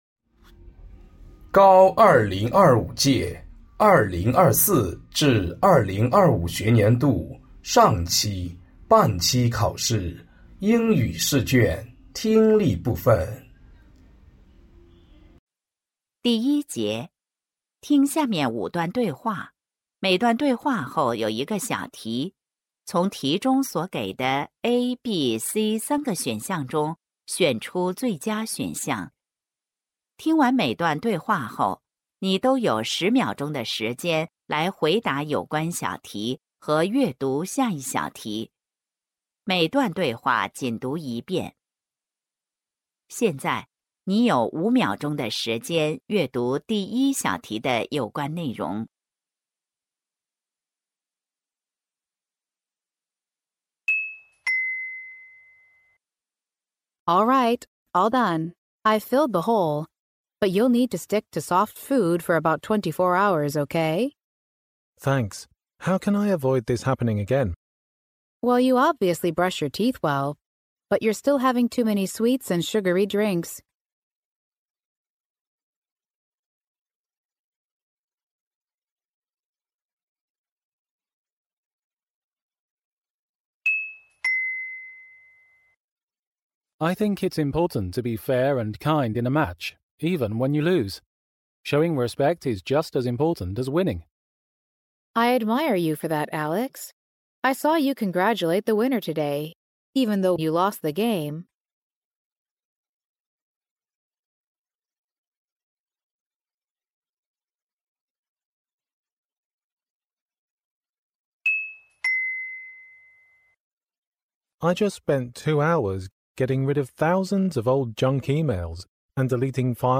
成都七中2025届高三上学期期中考试英语听力.mp3